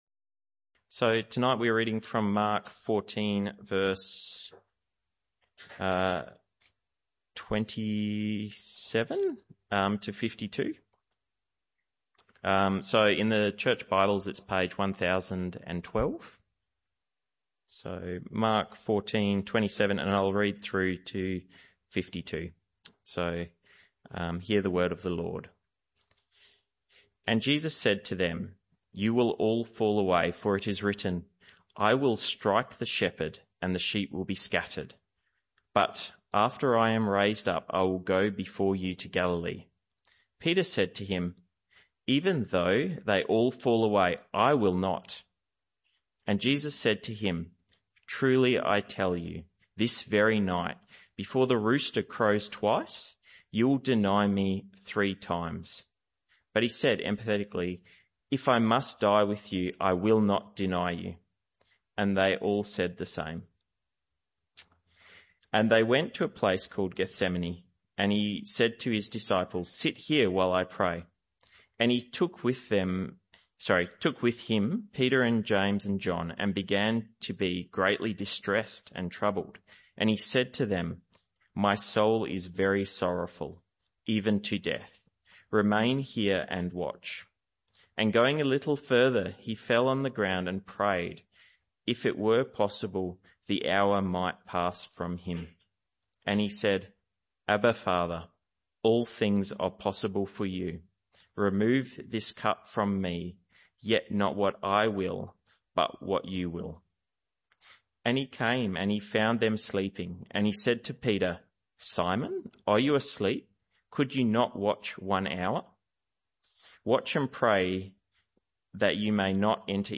This talk was part of the AM/PM Service series entitled The Way Of The Cross.
Service Type: Evening Service